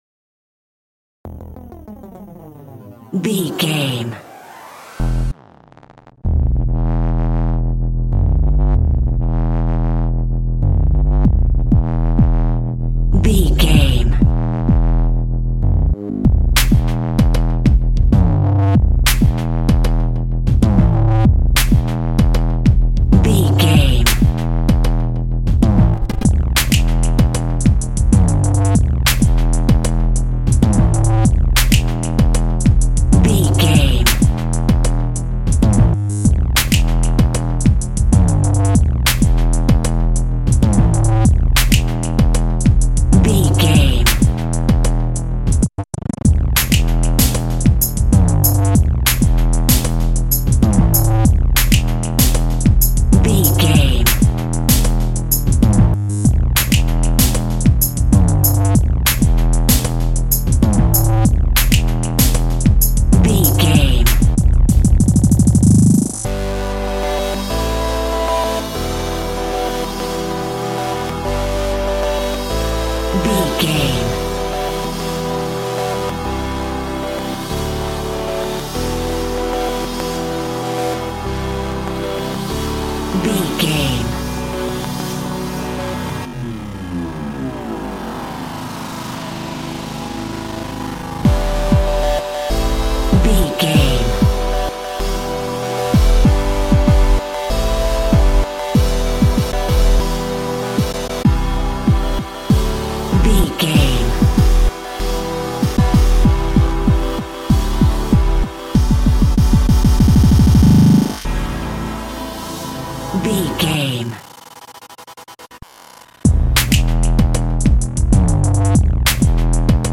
Epic / Action
Fast paced
Aeolian/Minor
aggressive
dark
intense
energetic
driving
synthesiser
drum machine
electronic
synth leads
synth bass